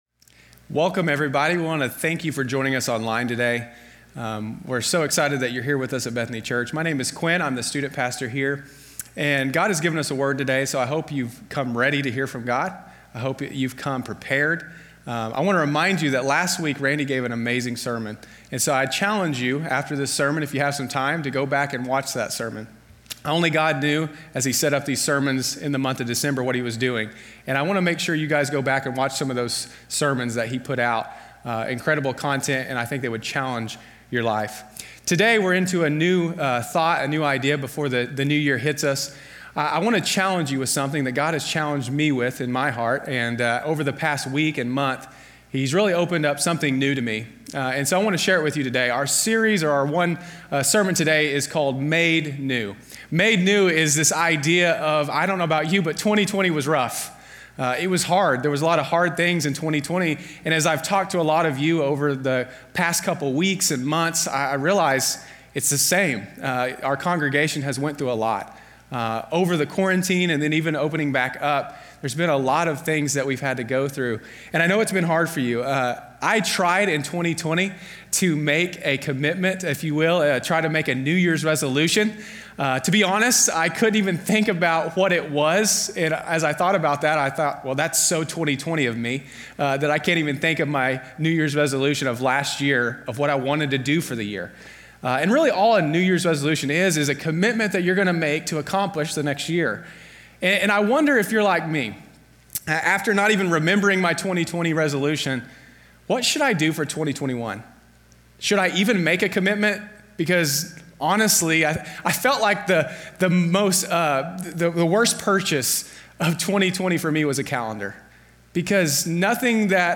Sermons that aren't a part of a series.